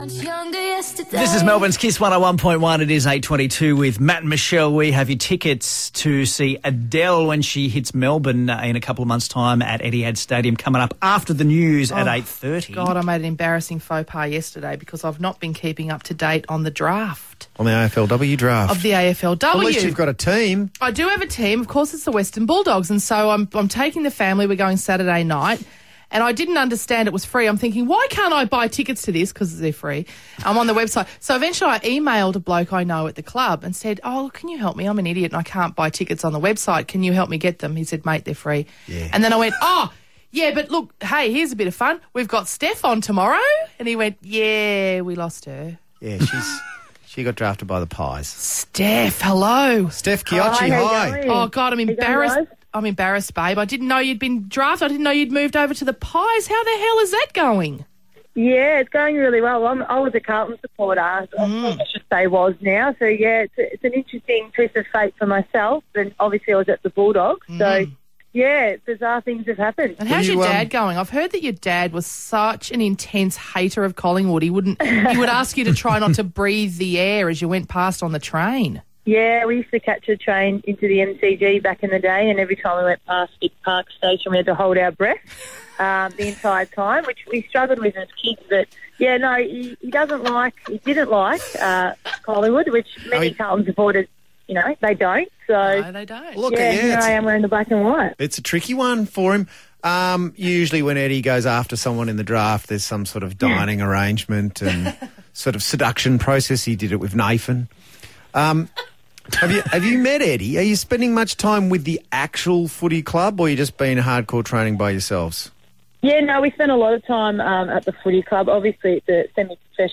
Radio: Steph Chiocci on KIIS FM
Listen to Collingwood AFL Women's captain Steph Chiocci talk to the team at KIIS FM.